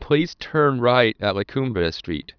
A demonstration of the speech quality with implanted prosody is available in waveform file ([WAVE stltts1.wav and stltts2.wav]) on the conference CD.
audio file demonstrating quality of synthesis method
Tech. description: sample rate = 11025, mono, linear encoding, 16 bits per sample